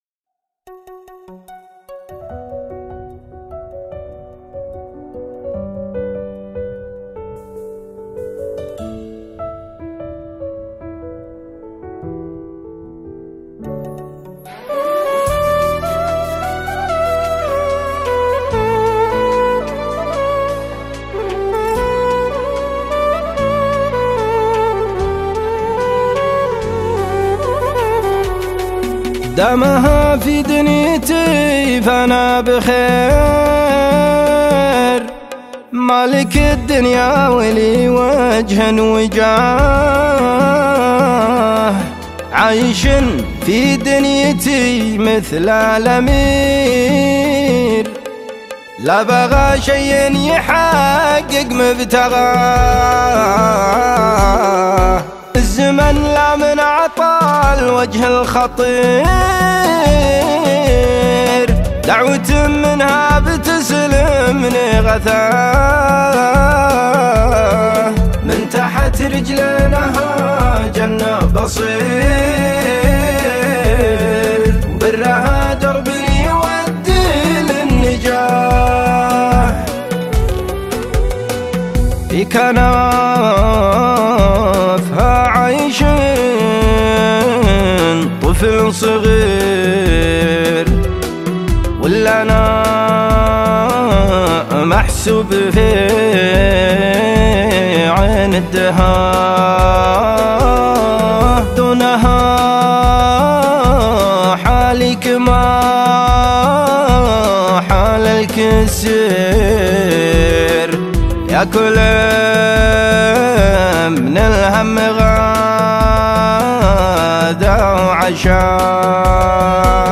شيلات حزينة